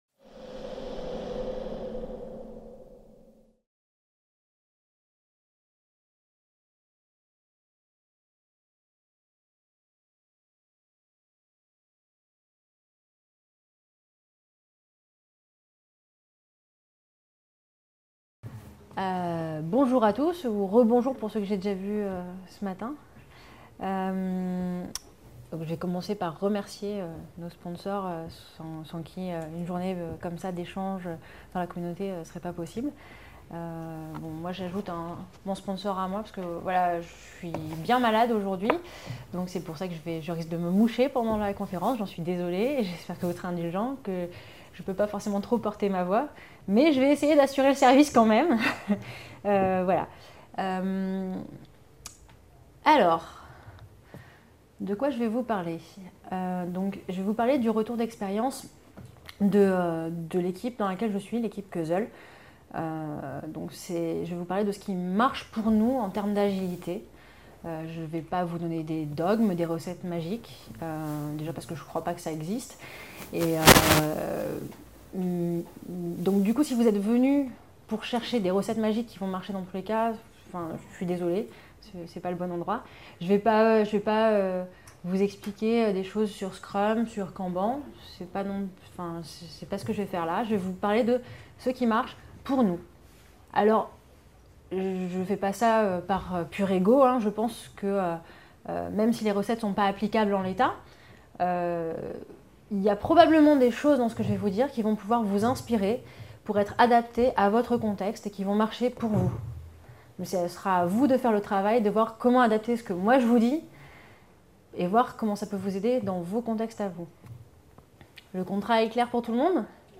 La conférence : Je vais vous raconter l’aventure humaine que notre équipe vit au quotidien, avec nos difficultés et nos réussites.